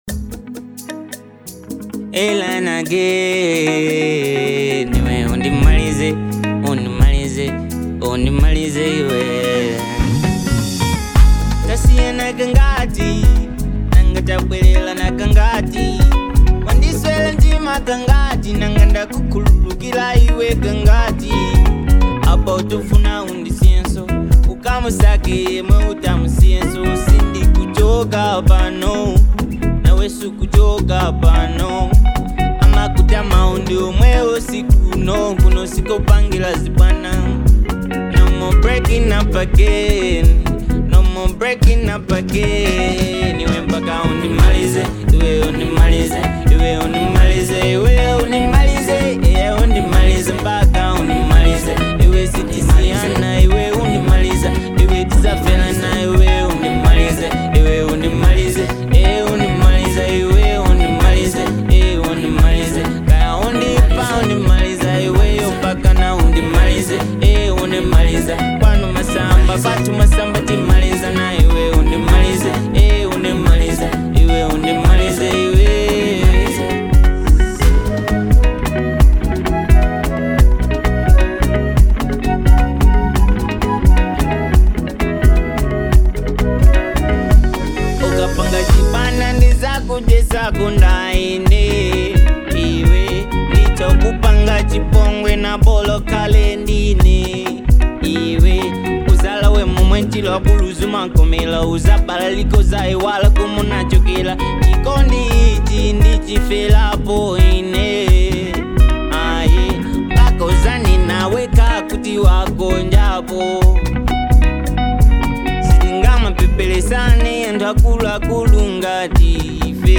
Fusion